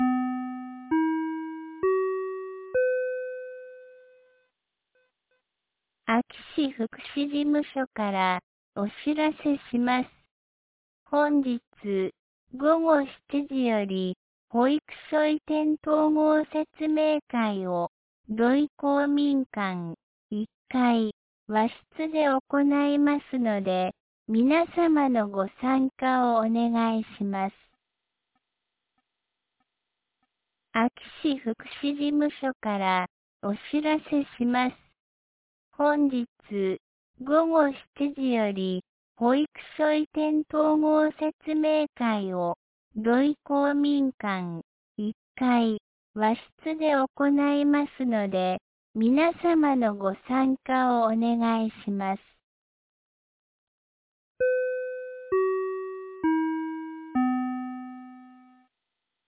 2026年02月19日 17時21分に、安芸市より土居、江川、僧津へ放送がありました。